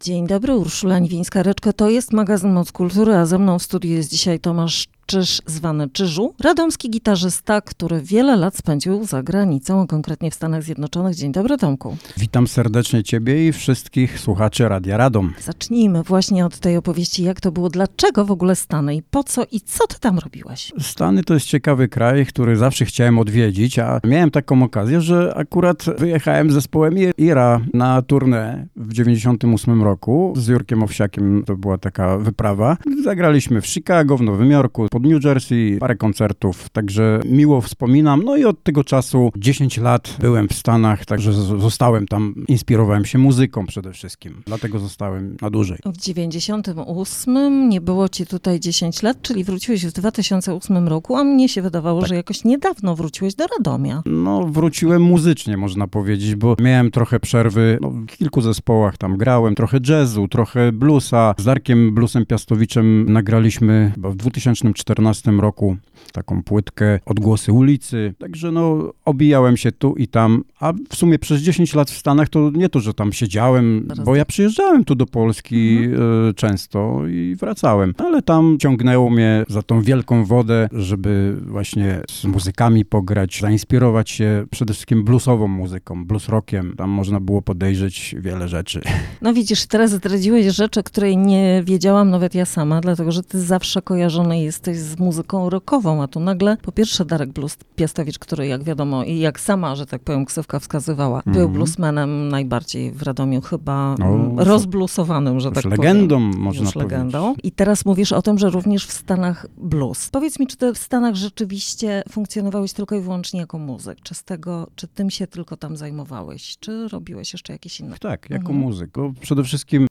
Materiał zawiera również muzyczną premierę